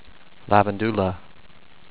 lav-en-DUE-la